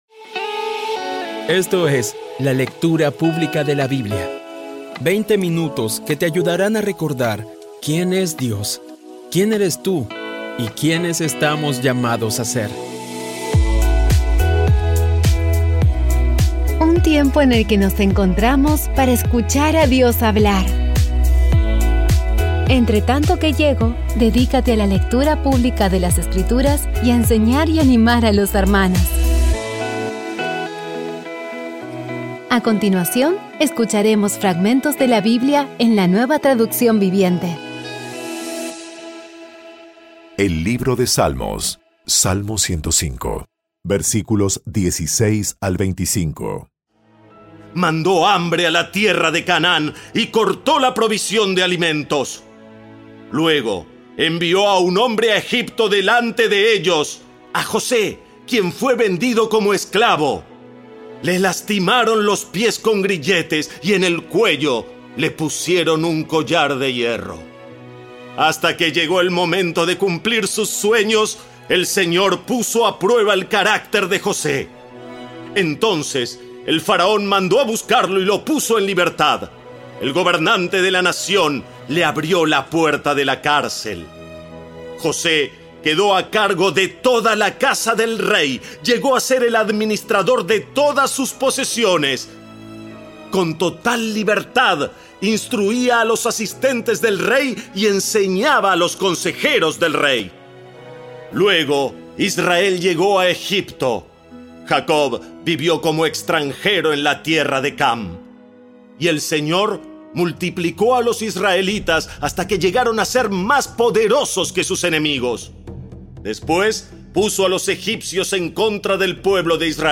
Audio Biblia Dramatizada Episodio 259
Poco a poco y con las maravillosas voces actuadas de los protagonistas vas degustando las palabras de esa guía que Dios nos dio.